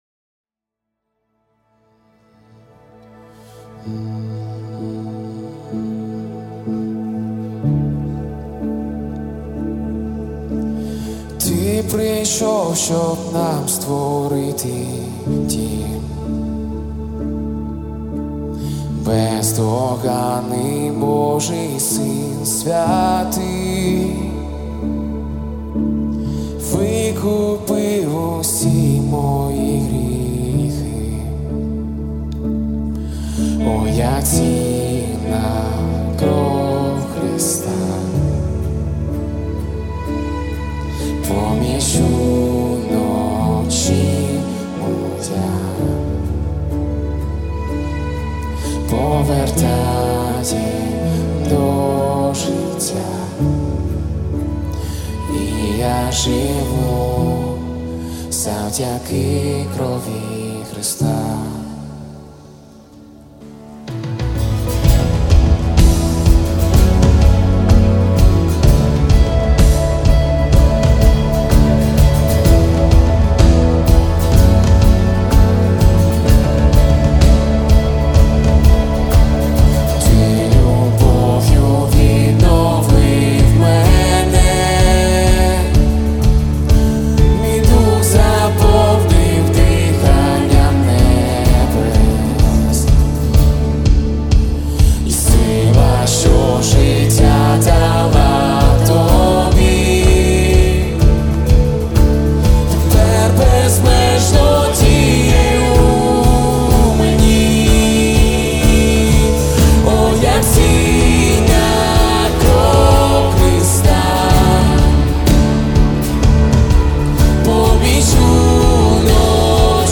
Worship Music
225 просмотров 174 прослушивания 31 скачиваний BPM: 189